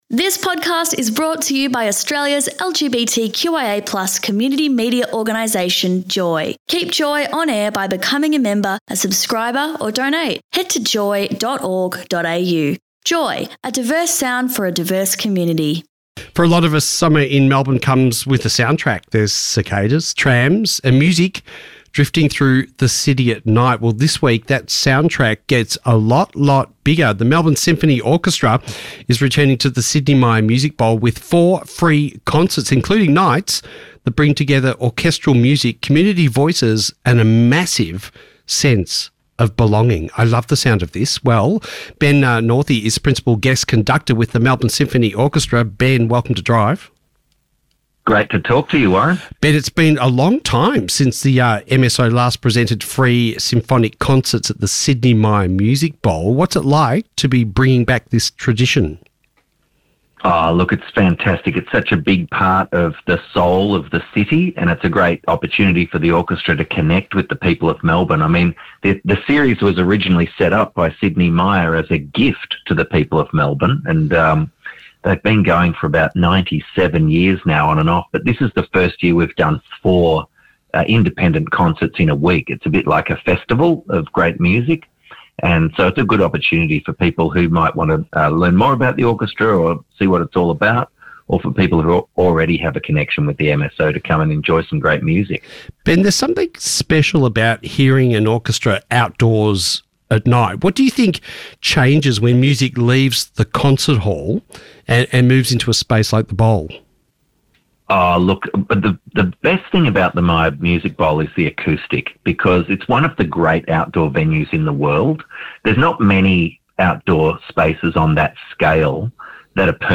Guest
Presenter